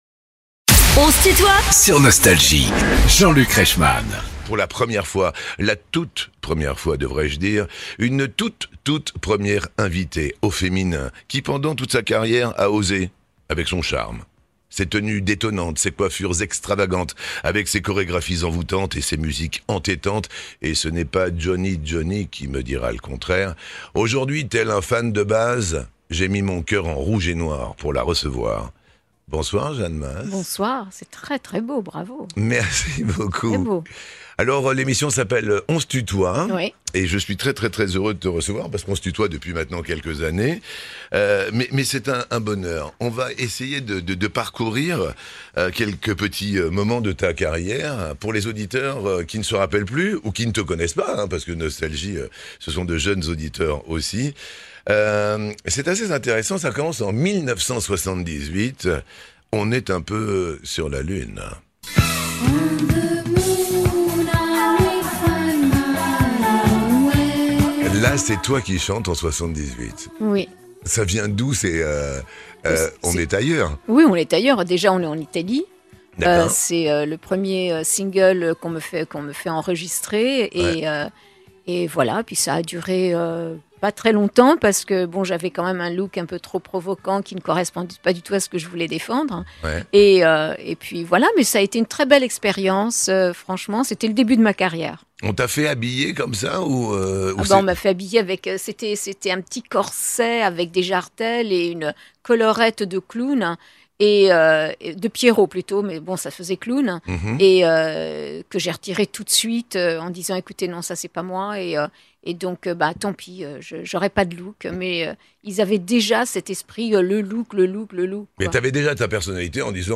Jeanne Mas est l'invitée de Jean-Luc Reichmann dans "On se tutoie ?..." sur Nostalgie ~ Les interviews Podcast